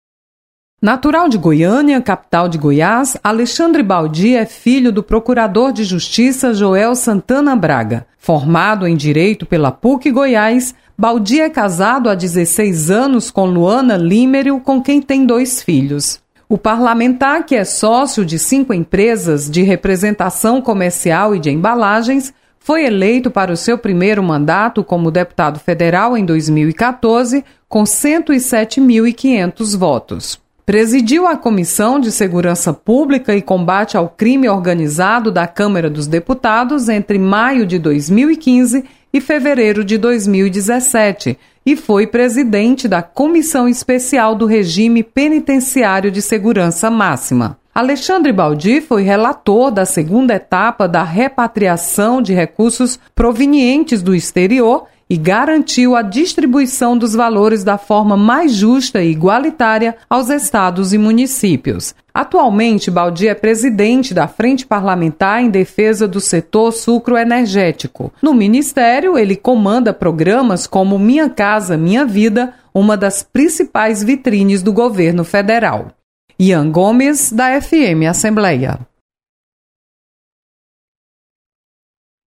Projeto concede título de cidadania ao ministro das Cidades. Repórter